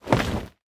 creatura_hit_1.ogg